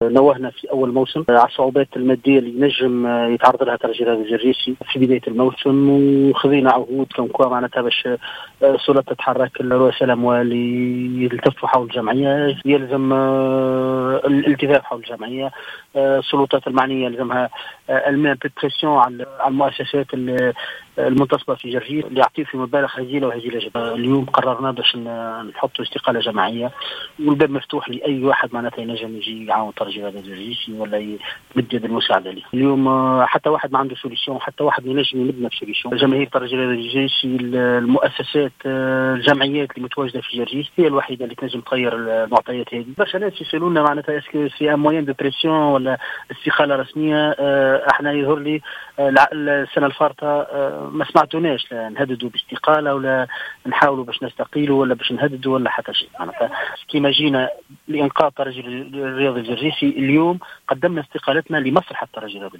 تصريح لجوهرة اف ام